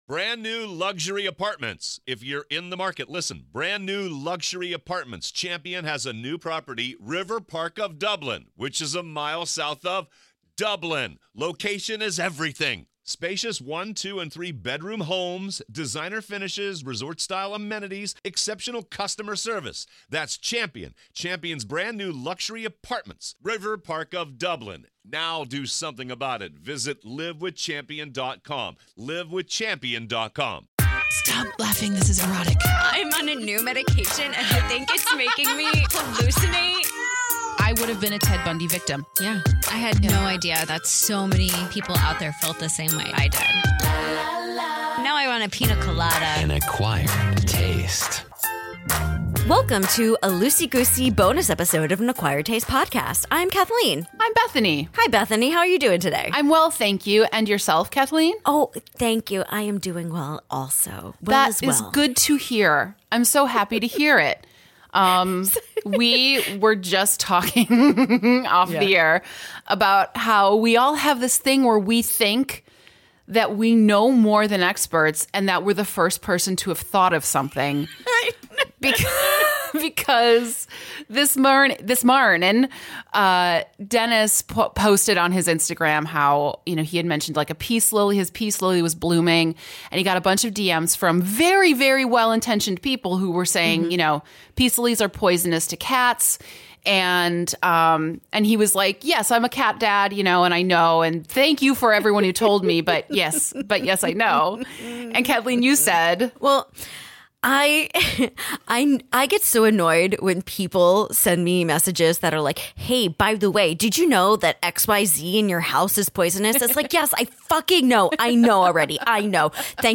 And... listen to all the Bonus Bud names from Patreon read at the end in our very amazing and very terrible accents!